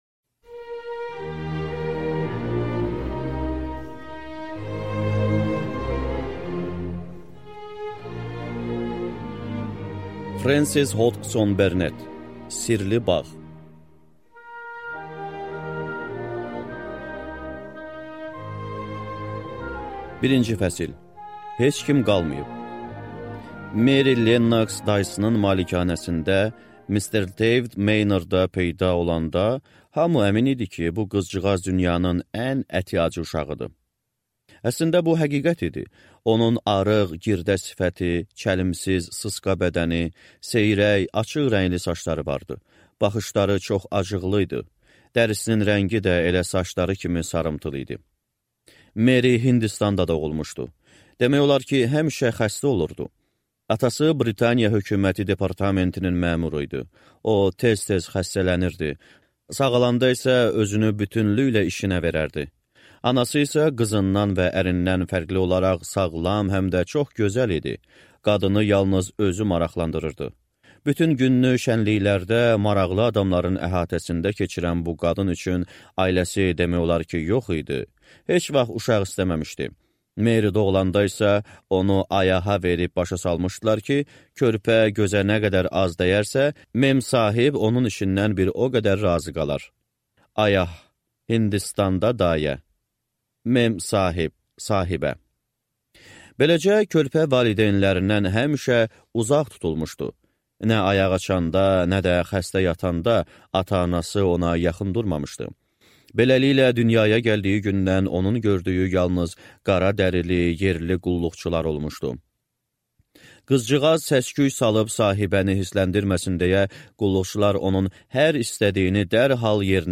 Аудиокнига Sirli bağ | Библиотека аудиокниг